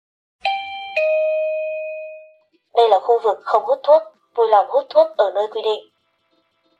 Tiếng chuông báo Đây là khu vực không hút thuốc, Vui lòng hút thuốc ở nơi quy định… (Giọng nữ)
Thể loại: Tiếng chuông, còi
tieng-chuong-bao-day-la-khu-vuc-khong-hut-thuoc-vui-long-hut-thuoc-o-noi-quy-dinh-giong-nu-www_tiengdong_com.mp3